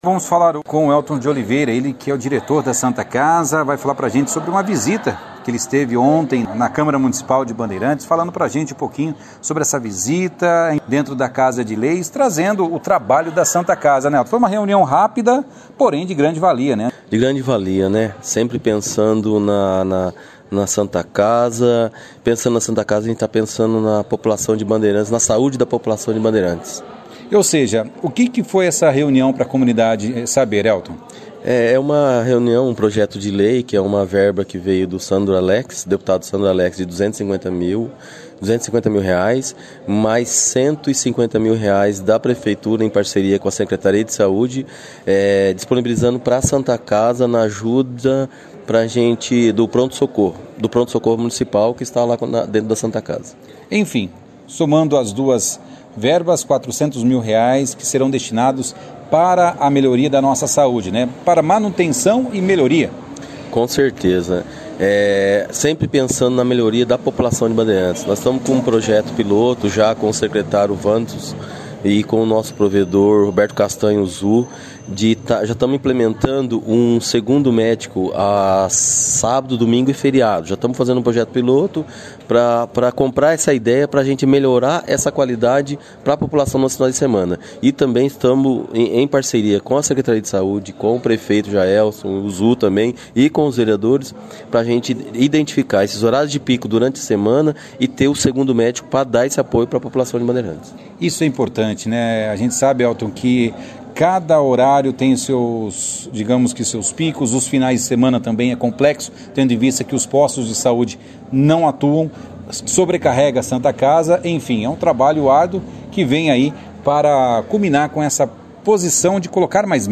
Realizada no plenário da Câmara de Vereadores de Bandeirantes, na última segunda-feira, 25/04, a 11ª sessão ordinária do ano legislativo de 2022. A sessão marcada pela leitura e votação de requerimentos e projetos de lei e a presença de professores da rede municpal e diretoria da Santa Casa.